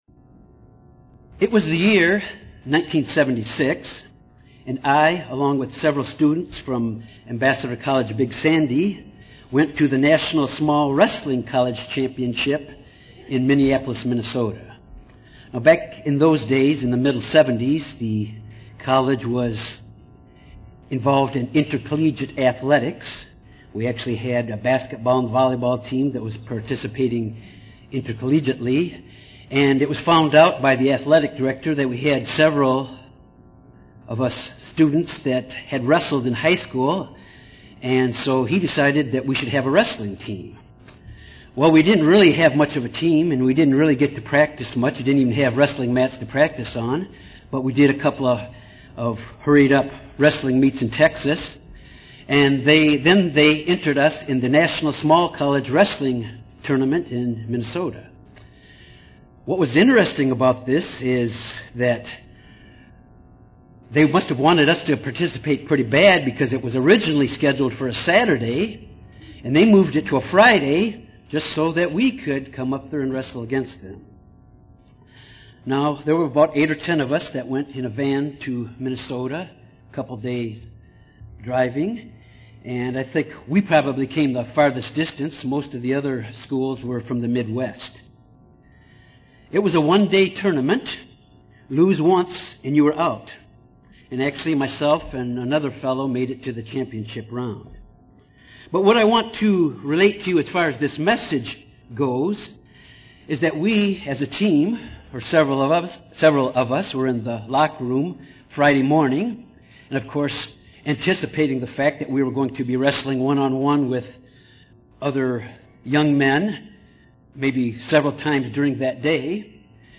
We have to be careful not to get caught up in this world’s complacency UCG Sermon Studying the bible?